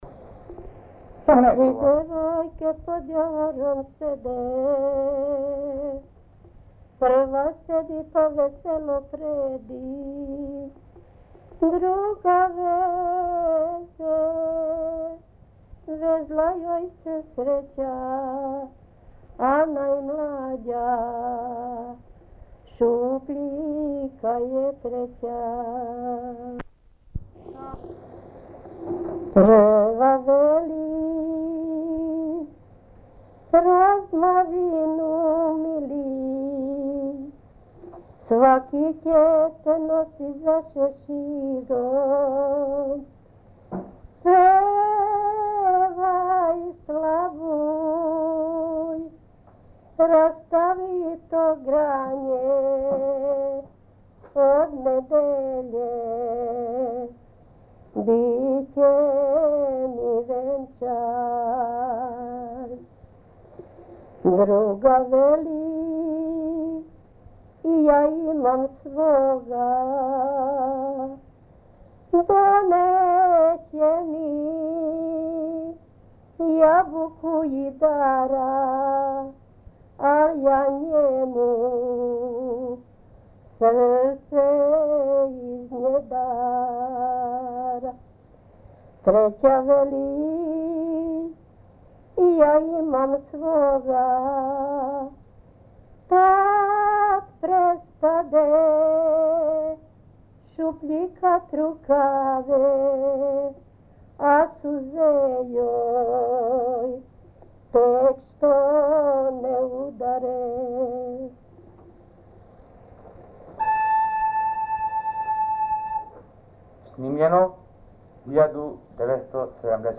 Dialektus: D
Téma: Epikus énekek, elbeszélő énekek, románcok, balladák
Helység: Szigetcsép (a felvétel Pestszentlőrincen készült)